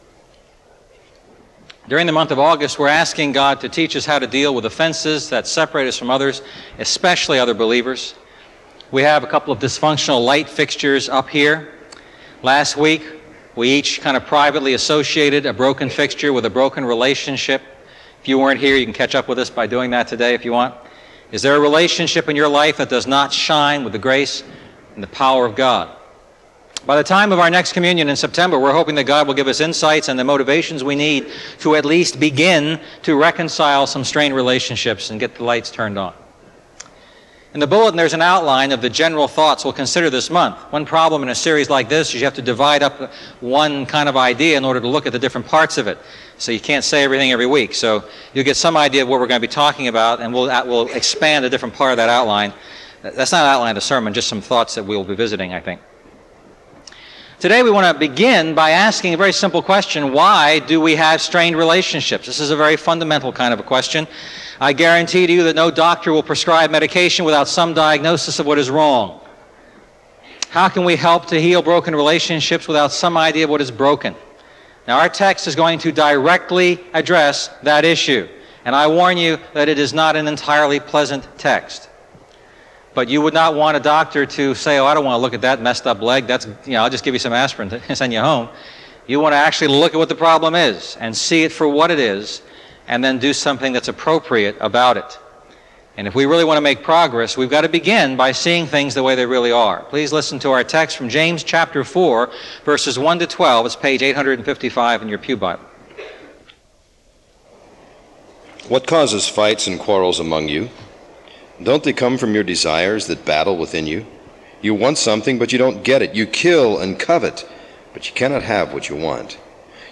Message: “What Causes Quarrels?